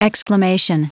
Prononciation du mot exclamation en anglais (fichier audio)
Prononciation du mot : exclamation